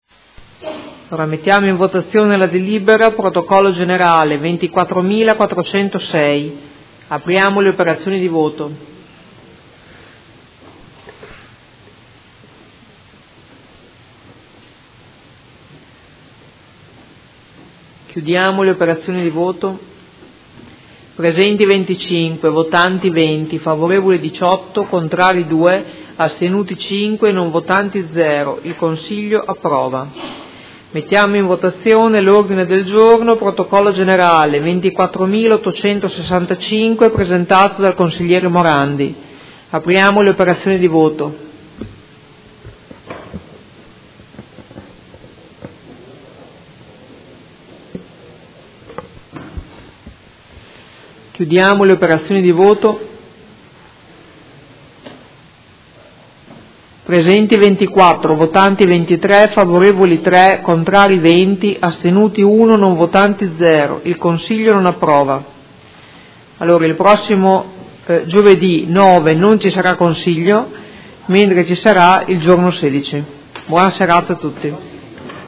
Presidentessa
Seduta del 02/03/2017. Mette ai voti: Integrazioni e chiarimenti in relazione al Documento di indirizzo “Sblocca Modena” Riqualificazione e riuso per l’occupazione.